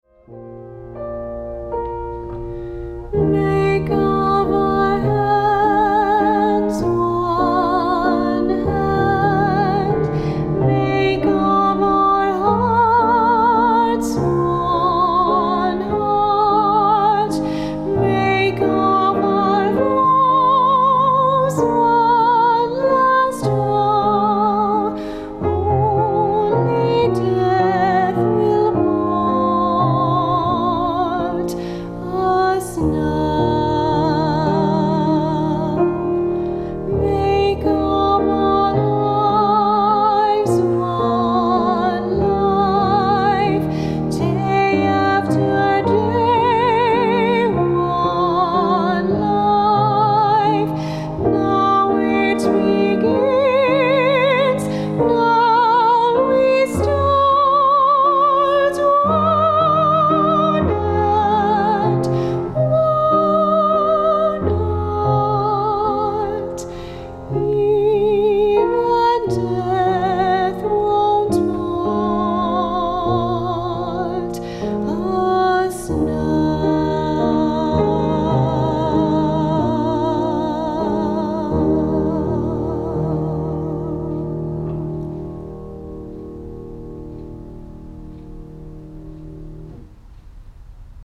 vocal
piano